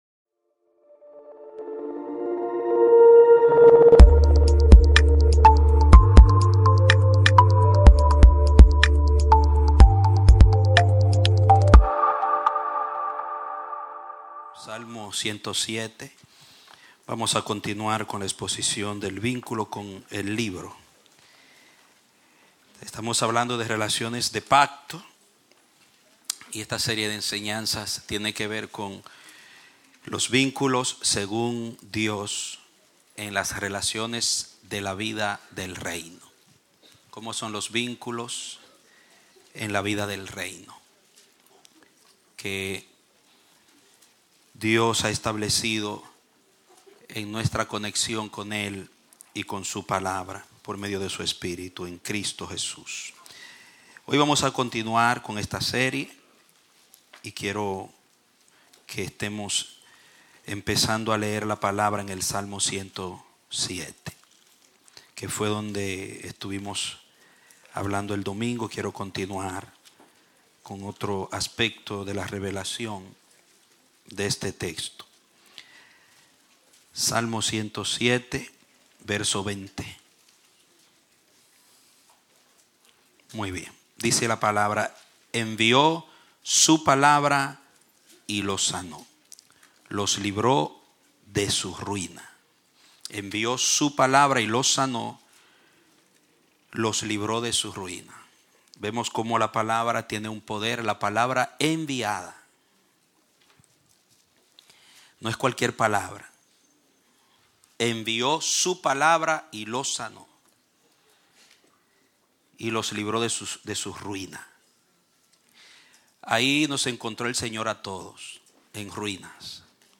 Clase Bautismal – El Amanecer de la Esperanza Ministry